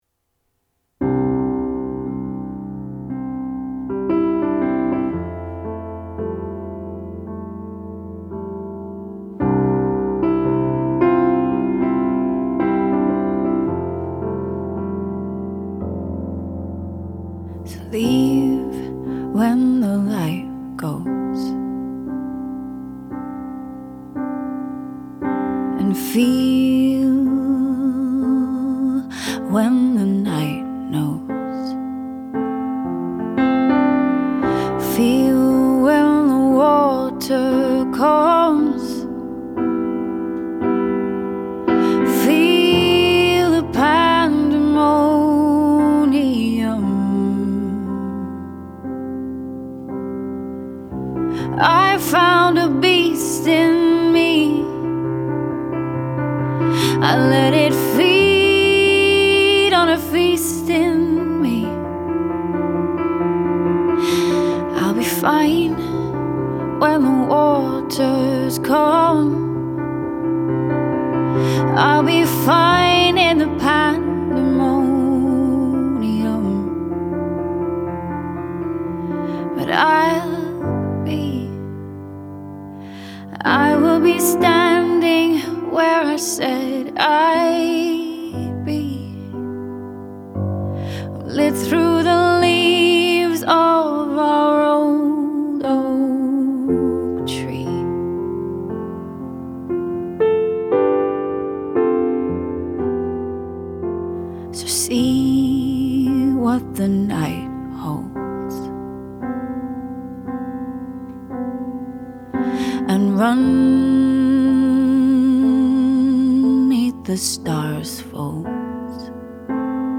singer-songwriter from Dublin